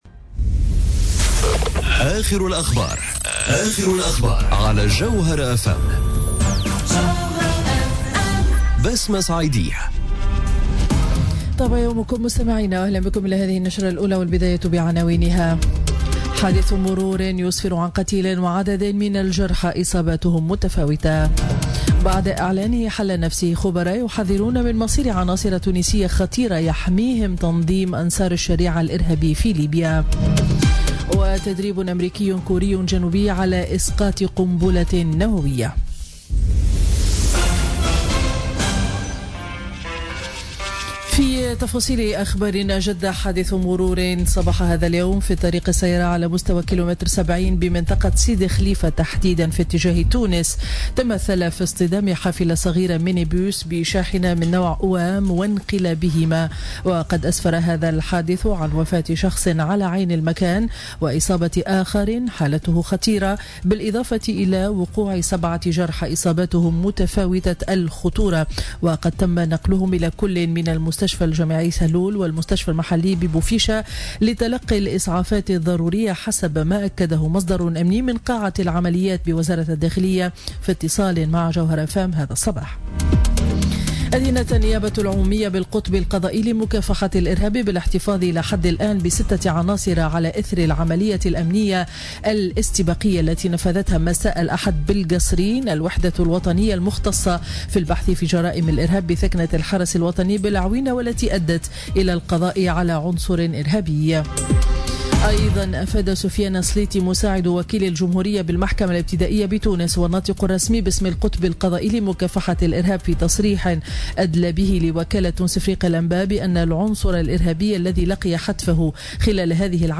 نشرة أخبار السابعة صباحا ليوم الثلاثاء 30 ماي 2017